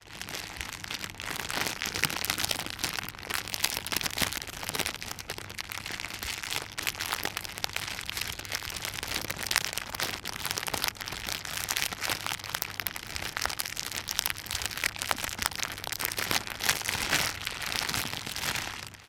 Звуки целлофана
Шуршание целлофанового пакета